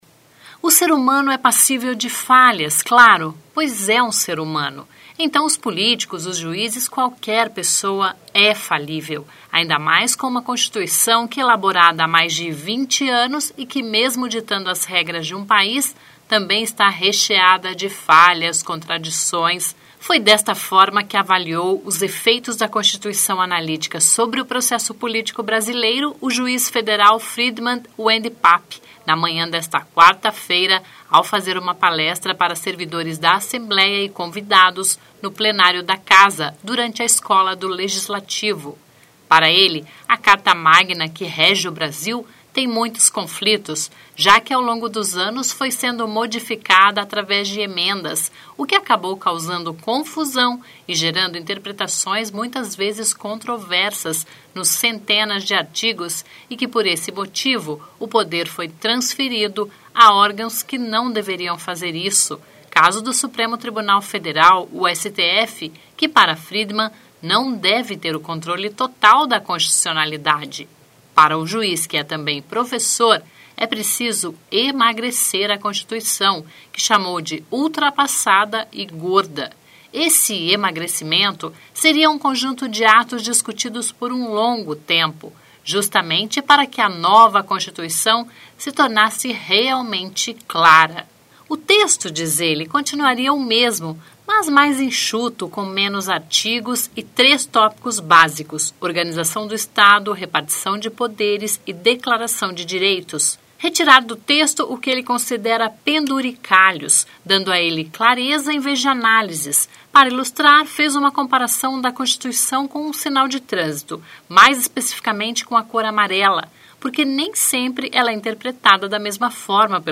Foi desta forma que avaliou os “Efeitos da Constituição Analítica Sobre o Processo Político Brasileiro”, o juiz federal, Friedmann Wendpap, na manhã desta quarta (23) ao fazer uma palestra para servidores da Assembleia, e convidados, no Plenário da Casa, durante a Escola do Legislativo.
Depois da palestra, o público fez perguntas ao juiz, que se disse instigado a pensar quando fala sobre o tema.